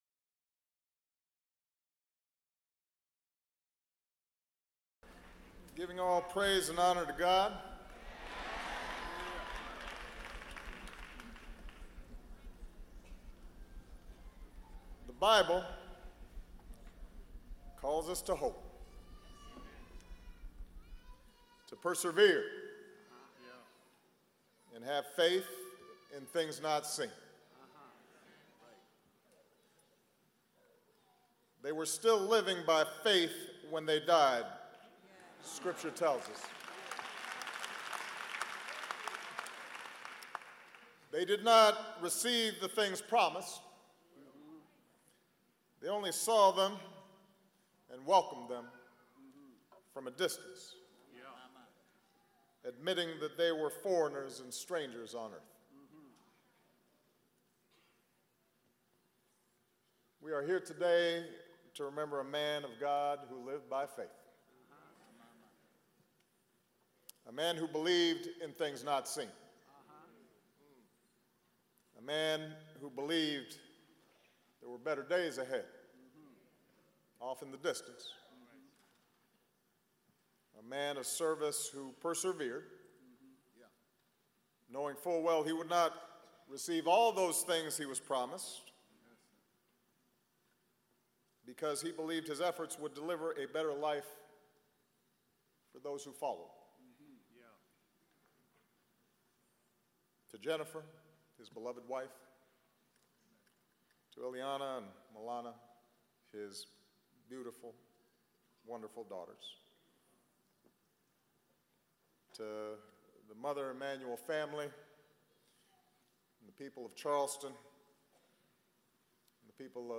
June 26, 2015: Remarks in Eulogy for the Honorable Reverend Clementa Pickney
Presidential Speeches